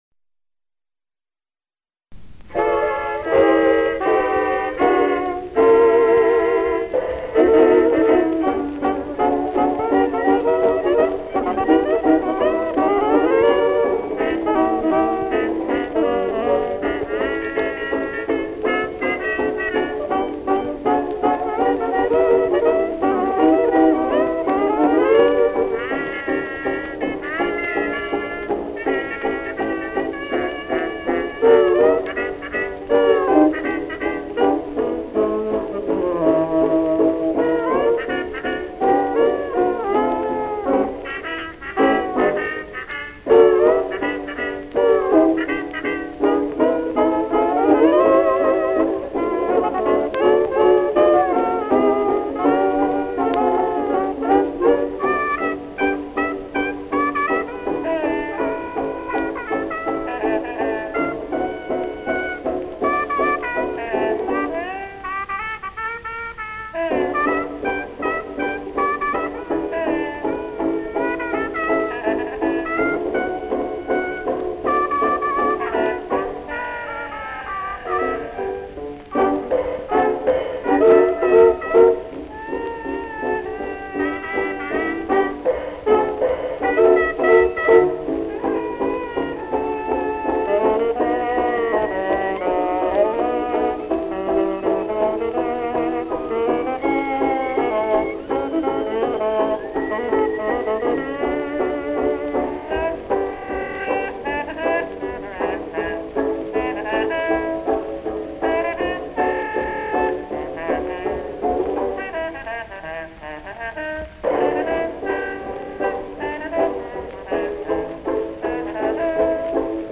Location Calcutta,India
trumpet
trombone
soprano/alto sax
clarinet
ten. sax
violin
piano
banjo
percussion